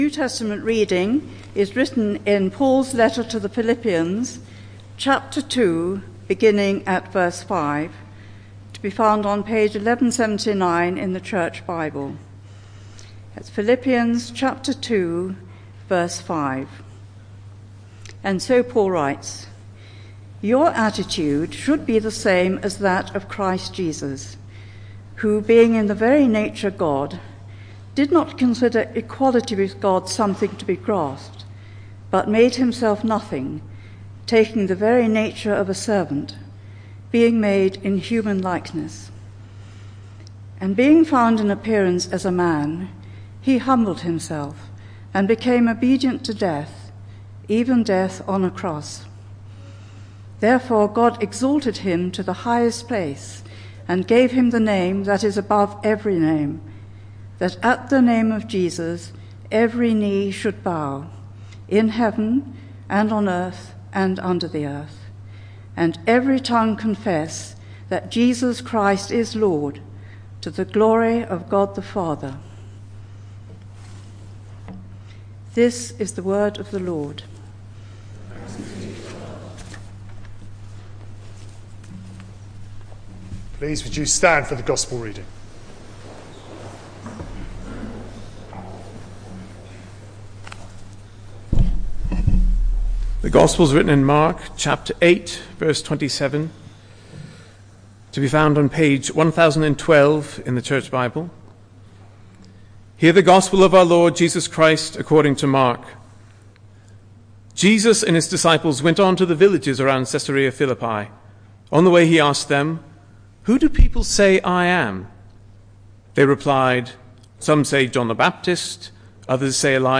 Confirmation Service